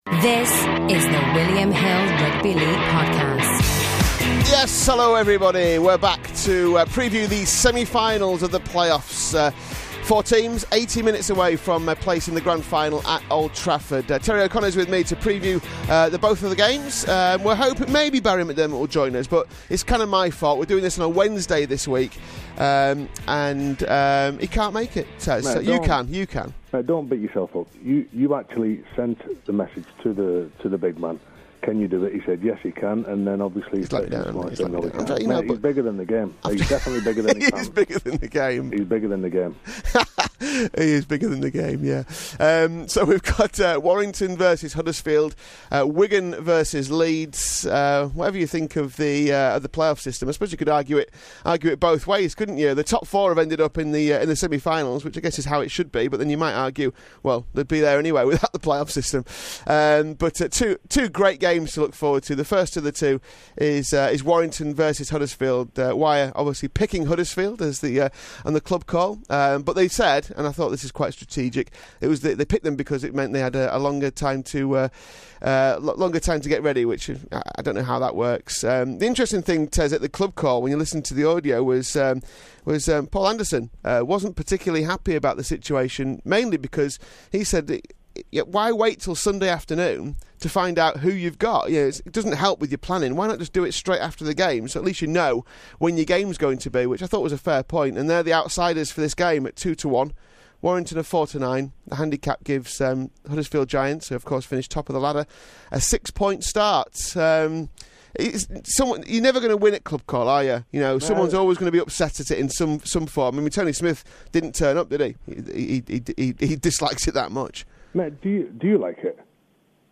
We've asked former GB prop Terry O'Connor for his predictions on Warrington v Huddersfield and Wigan v Leeds.